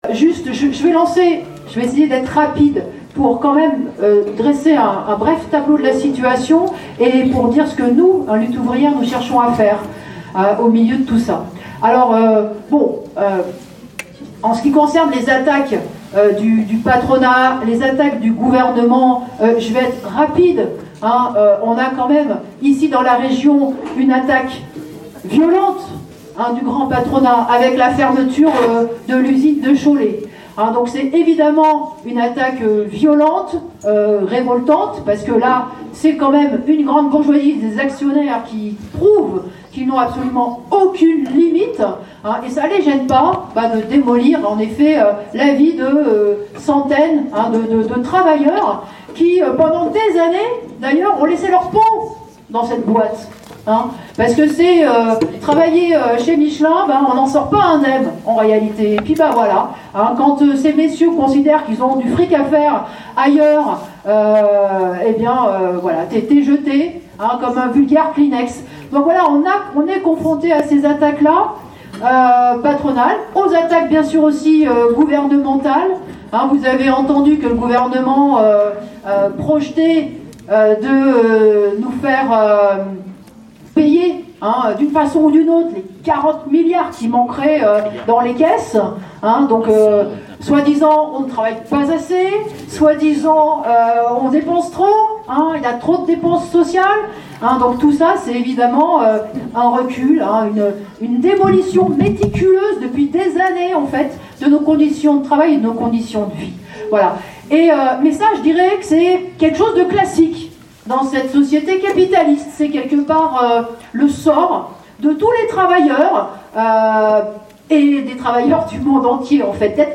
Fête d'Angers : Intervention de Nathalie Arthaud
Samedi 26 avril, la fête annuelle de Lutte ouvrière à Trélazé a réuni près de 300 personnes. Nathalie Arthaud y a animé un débat dont des extraits sont présentés ici.